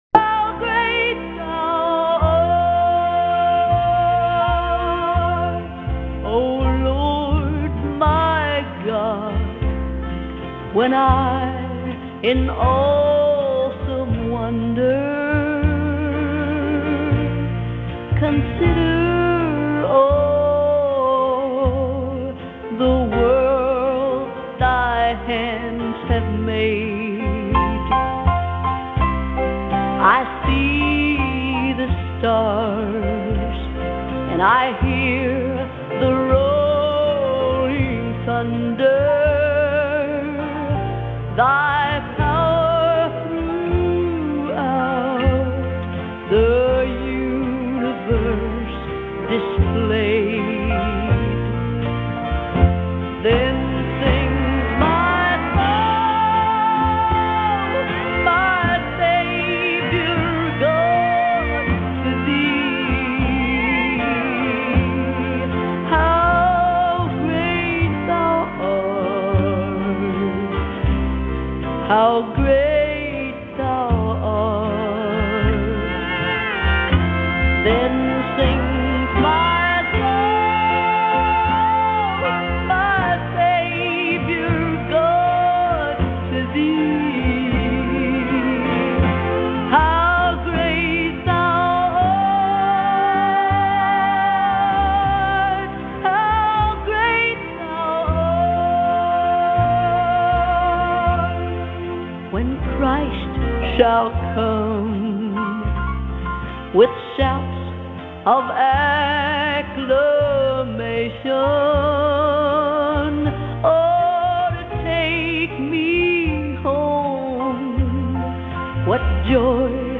Final Service for 40-Day
Partook in Communion Pastors led everyone in a commissioning ceremony.